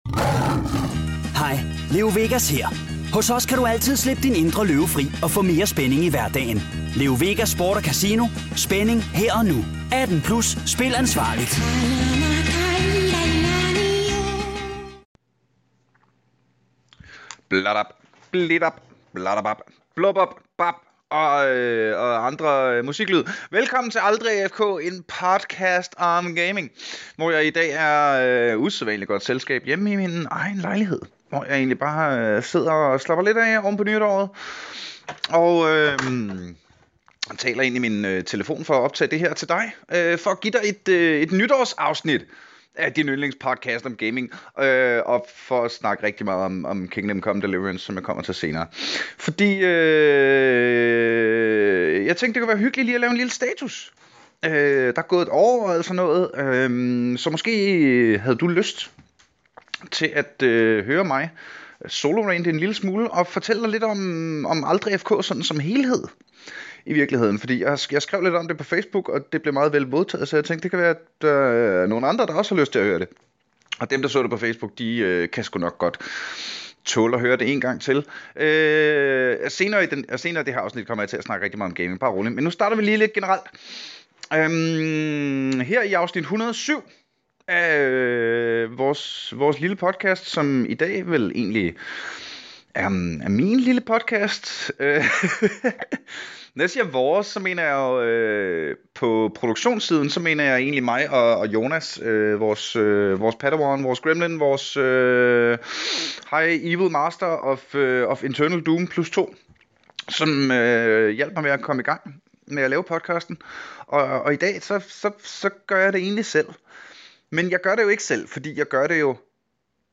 Solorant og middelalder-Skyrim ~ Aldrig AFK Podcast